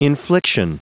Prononciation du mot infliction en anglais (fichier audio)
Prononciation du mot : infliction